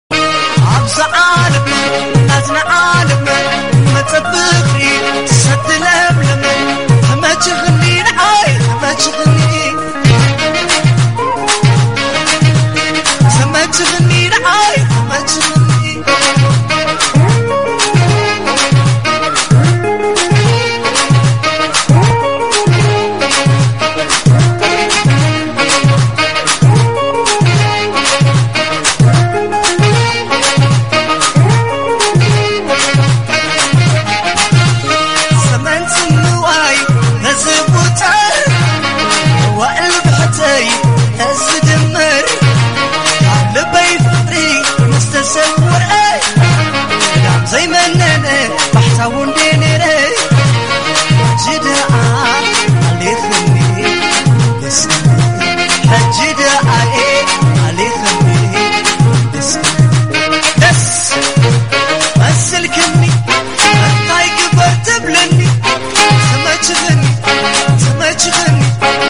old tigrigna music